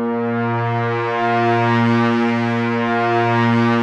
P.5 A#3 7.wav